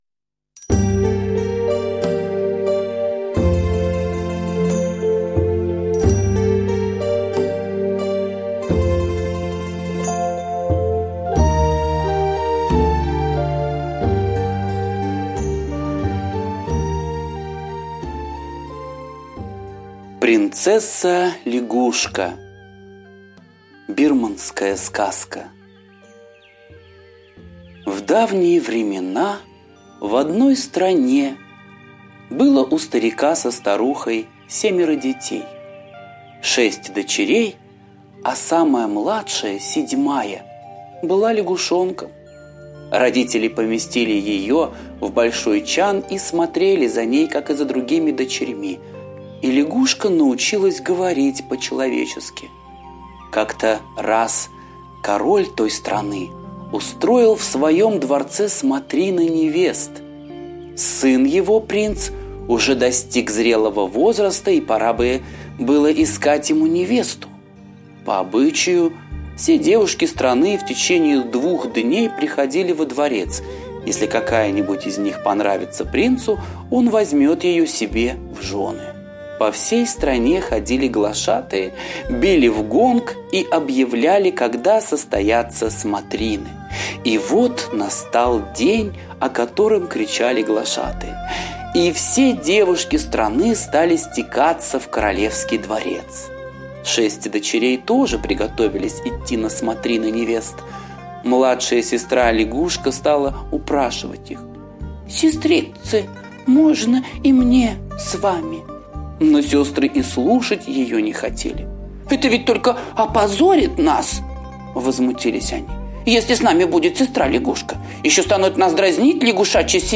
Принцесса-лягушка - восточная аудиосказка - слушать онлайн